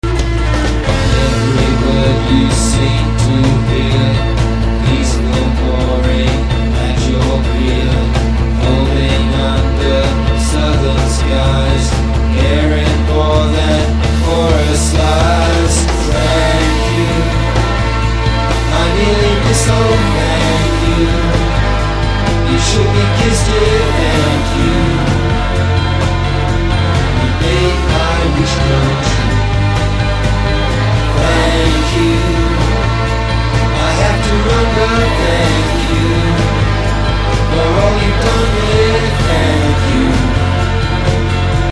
Mixed with deft textural manipulation.